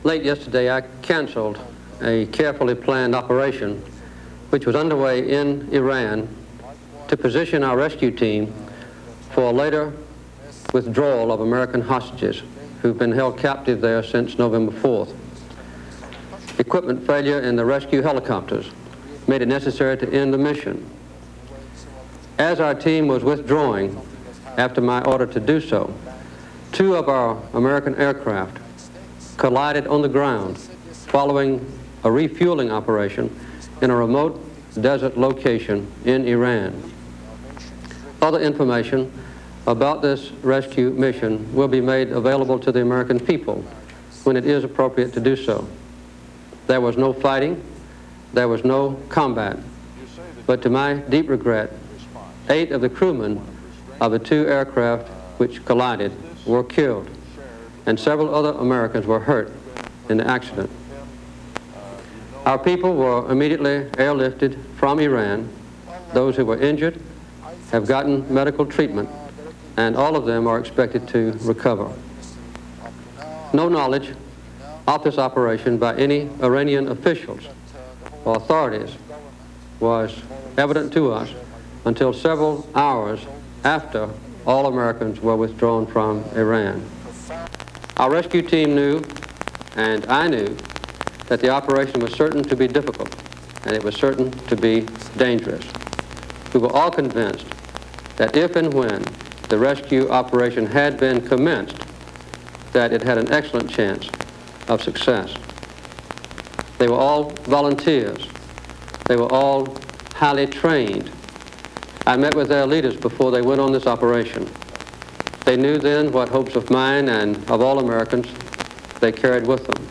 Jimmy Carter tells the nation of the abortive rescue by helicopter mission to free the hostages in Iran